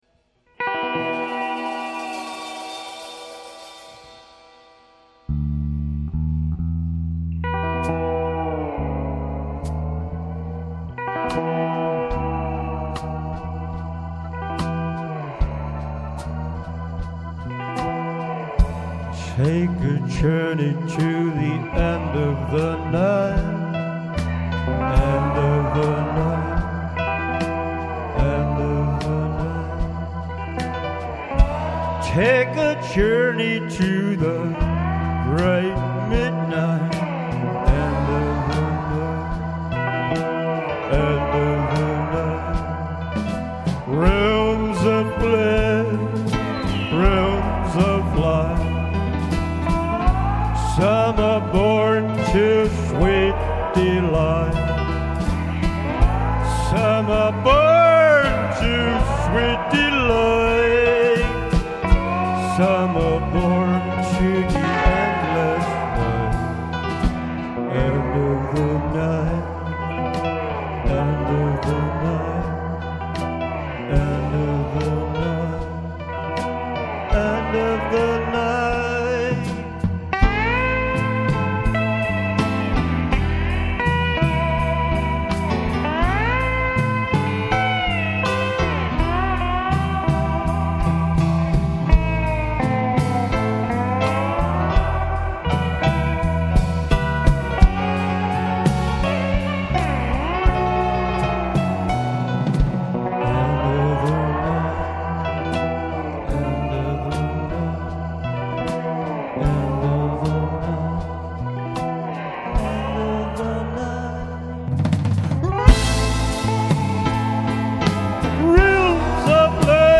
Older mp3s with bass guitar player